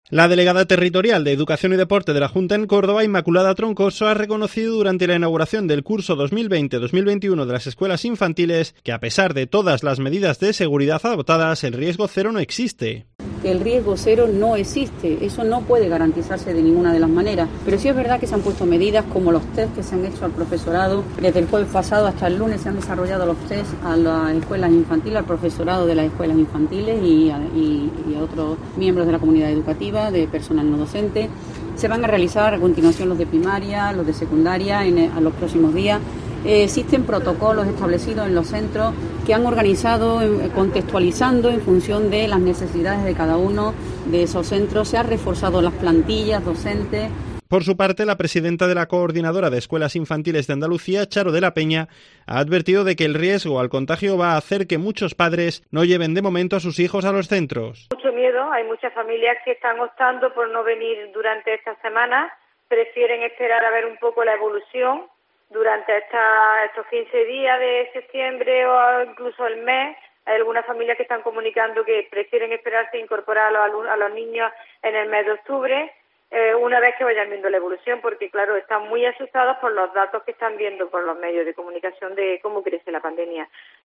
La delegada territorial de Educación y Deporte de la Junta en Córdoba, Inmaculada Troncoso, ha reconocido durante la inauguración del curso 2020/21 de las escuelas infantiles que a pesar de todas las medidas de seguridad adoptadas el riesgo cero no existe.